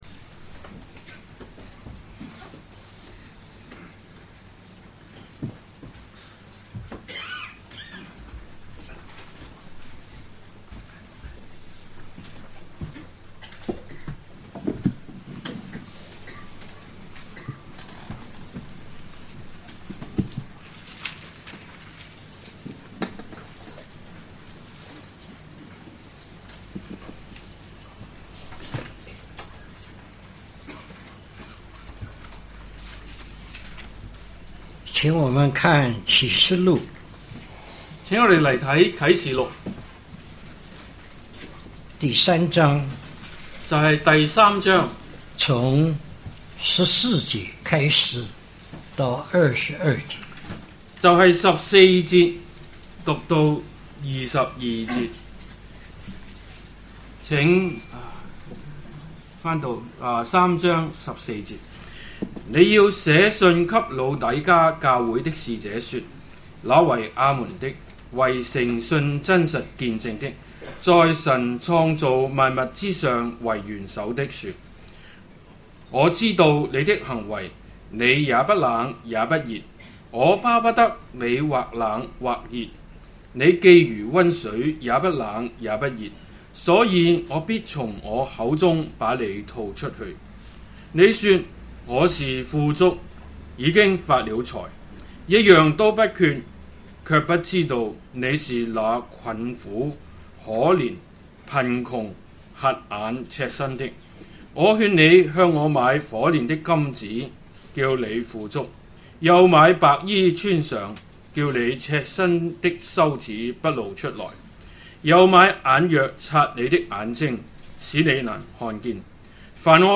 特會信息